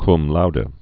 (km loudə, loudē, kŭm lôdē)